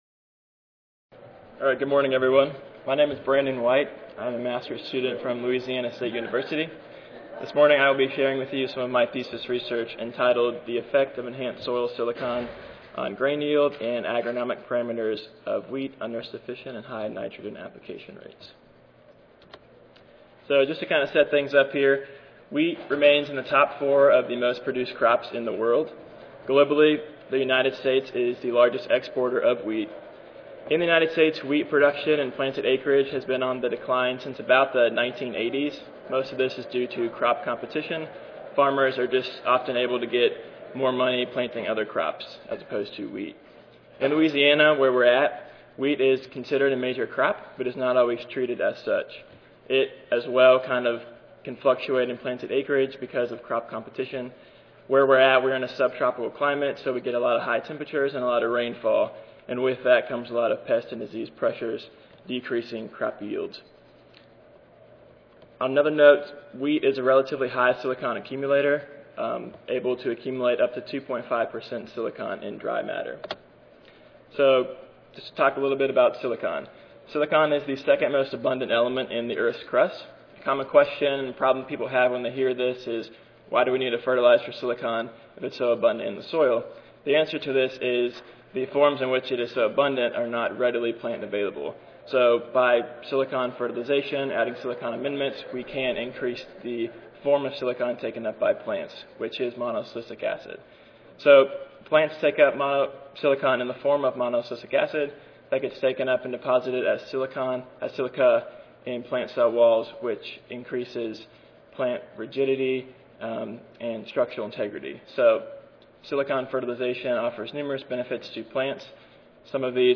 SSSA Division: Soil Fertility & Plant Nutrition Session: M.S. Graduate Student Oral Competition: I (ASA, CSSA and SSSA International Annual Meetings)
Recorded Presentation